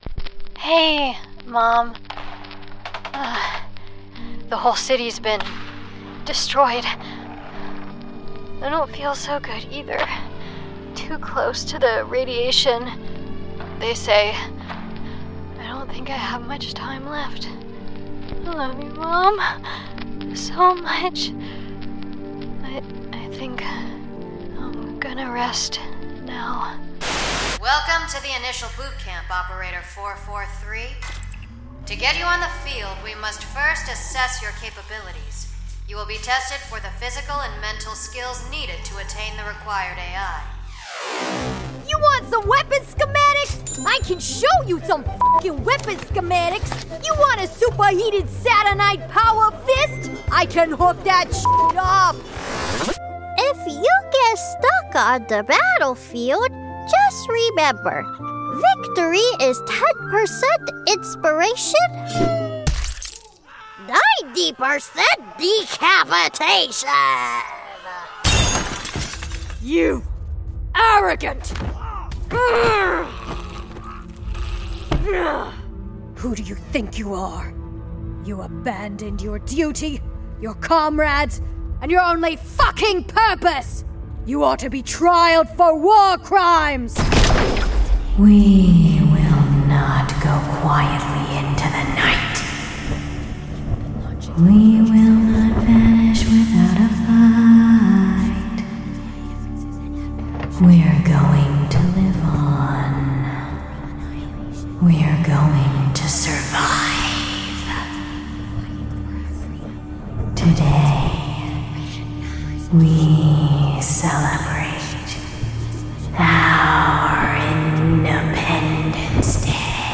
Wonderfully Weird. Fresh. Unconventional
Videogame
I have a wonderfully weird, bouncy voice that inspires and delights my collaborators.